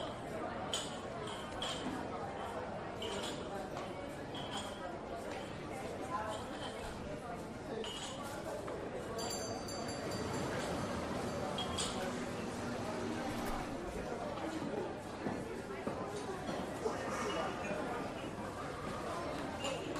Restaurant Noise
Restaurant Noise is a free ambient sound effect available for download in MP3 format.
# restaurant # crowd # noise About this sound Restaurant Noise is a free ambient sound effect available for download in MP3 format.
395_restaurant_noise.mp3